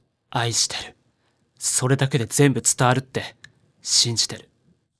男性
ボイス